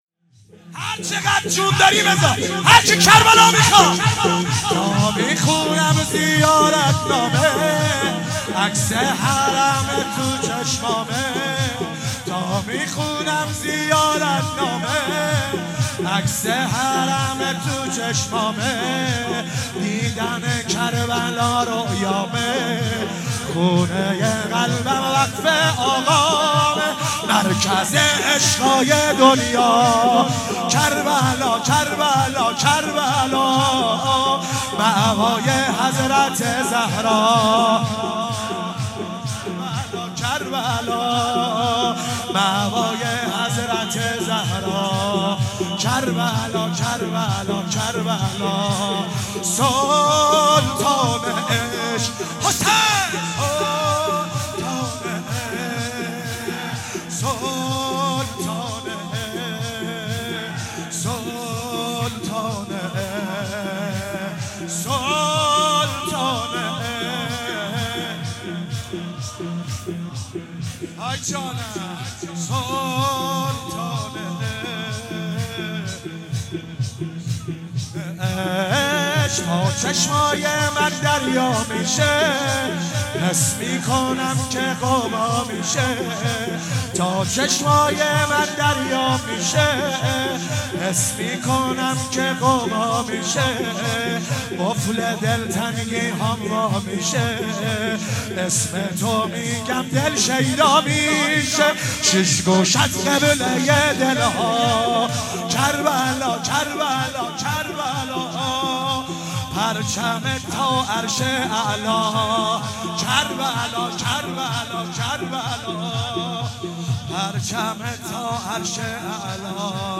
شب 19 ماه مبارک رمضان 96(قدر) - شور - تا میخونم زیارت نامه
شور مداحی